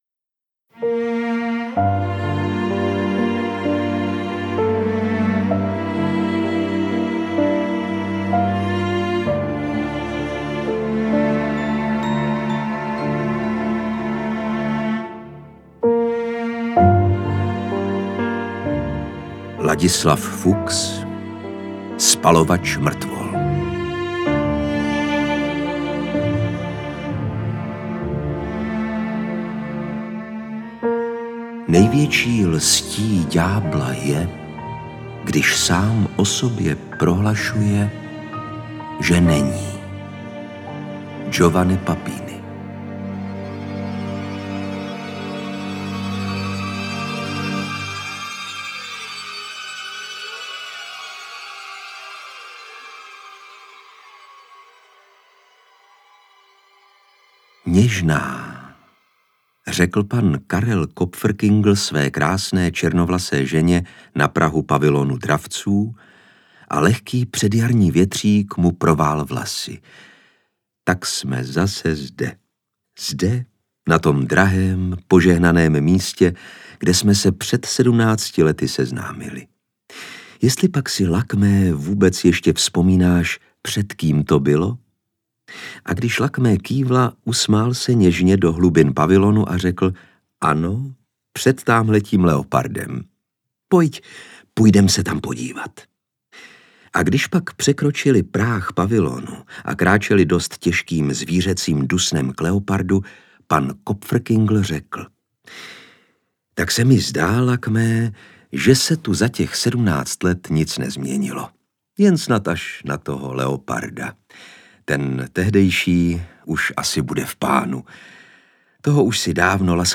Interpret:  Lukáš Hlavica
AudioKniha ke stažení, 14 x mp3, délka 4 hod. 53 min., velikost 670,3 MB, česky